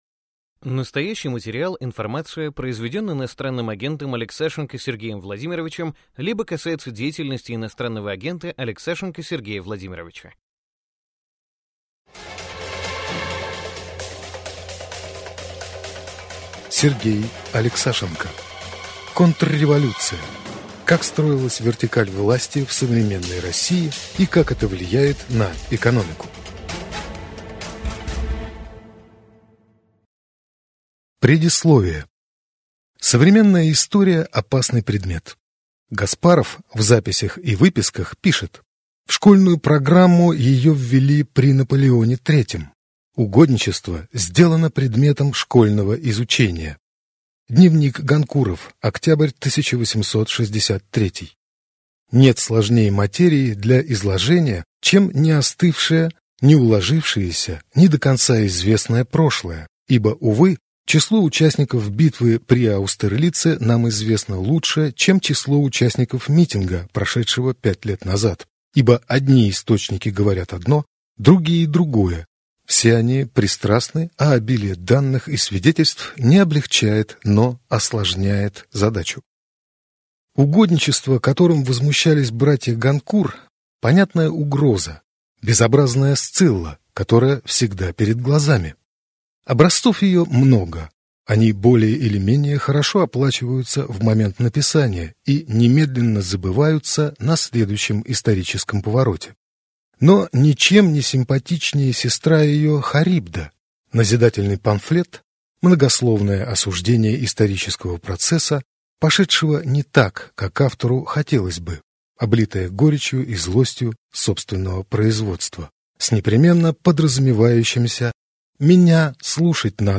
Аудиокнига Контрреволюция. Как строилась вертикаль власти в современной России и как это влияет на экономику | Библиотека аудиокниг